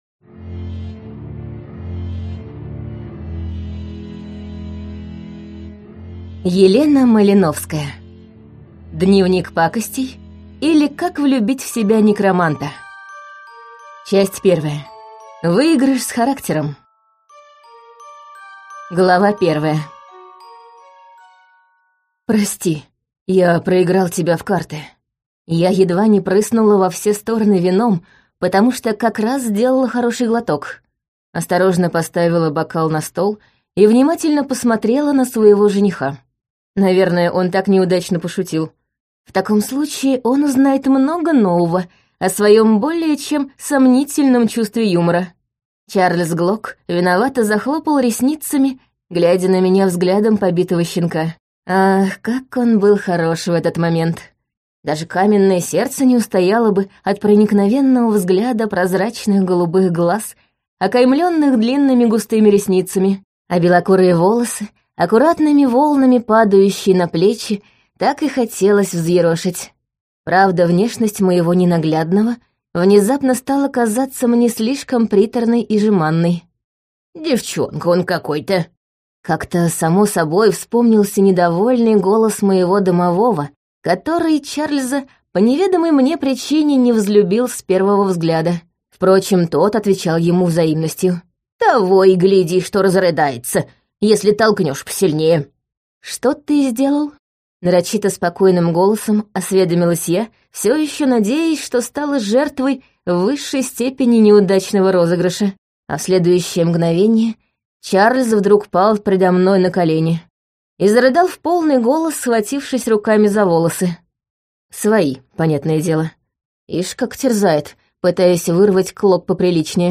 Аудиокнига Дневник пакостей, или Как влюбить в себя некроманта | Библиотека аудиокниг